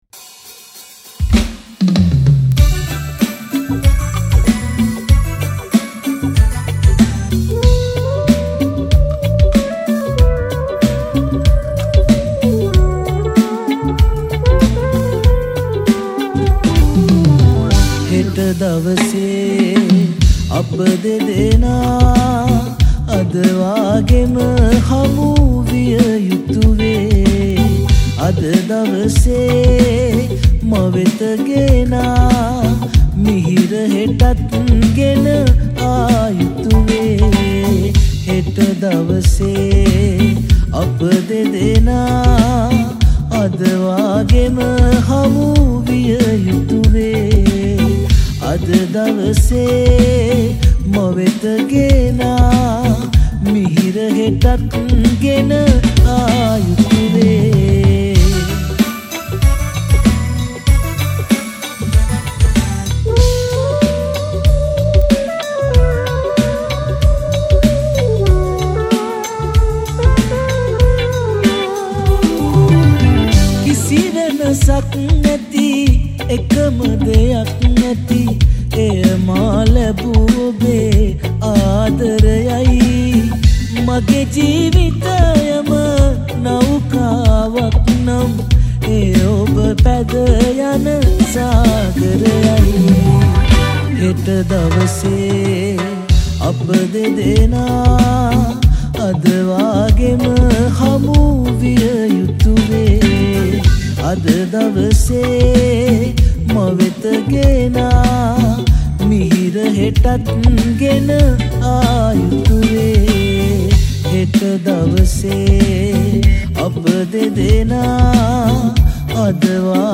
Vocal
Keybordist
Lead Guitarist
Bass Gutarist
Percussionist
Drummer